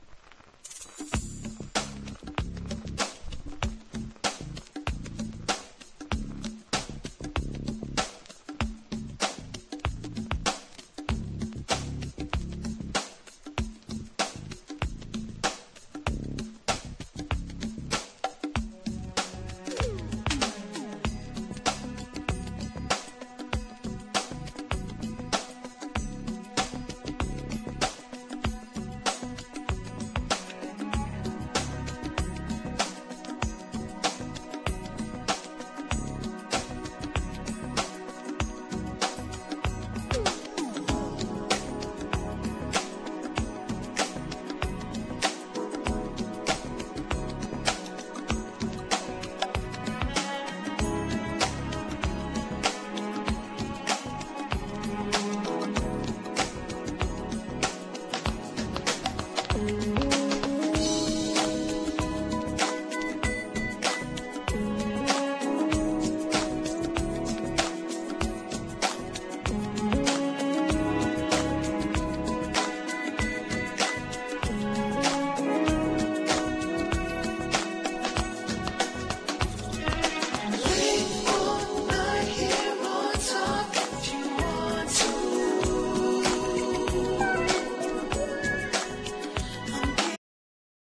ニューソウル･クラシック！！